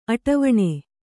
♪ aṭavaṇe